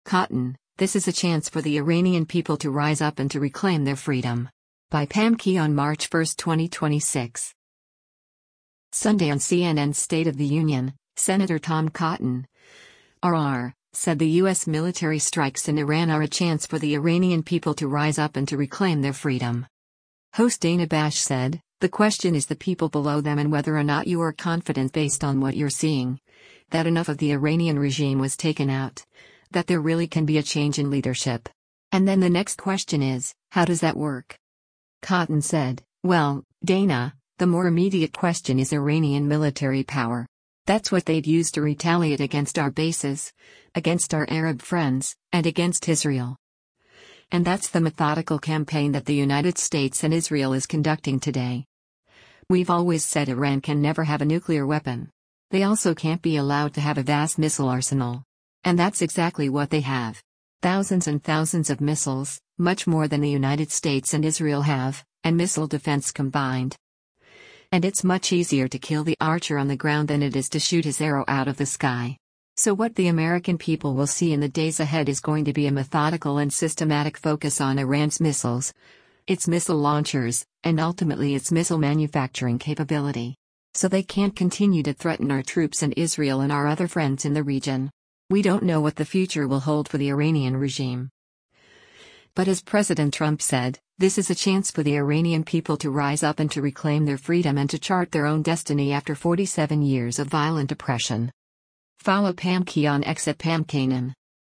Sunday on CNN’s “State of the Union,” Sen. Tom Cotton (R-AR) said the U.S. military strikes in Iran are “a chance for the Iranian people to rise up and to reclaim their freedom.”